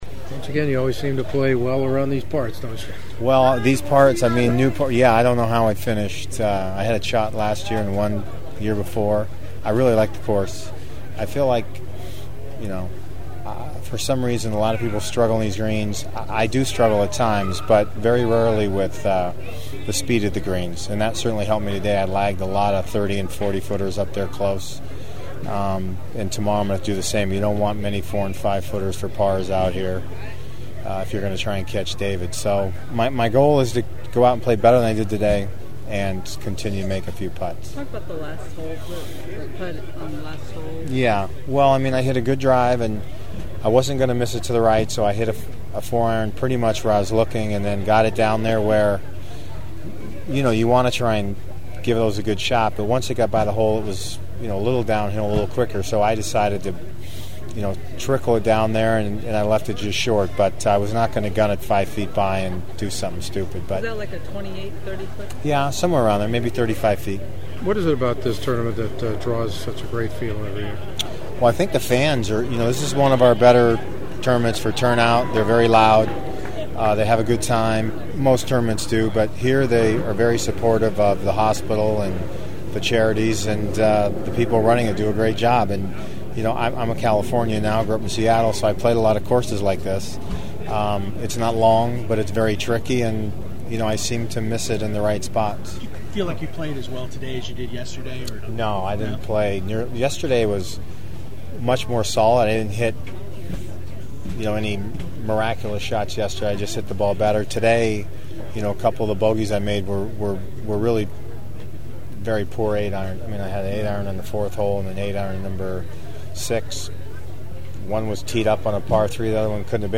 Some of you know of my issues with Couples going back almost 20 years and today is the first time I’ve interviewed him since the mid 1990’s (I only did it because I like a few of the people who run this event and it helps them out!).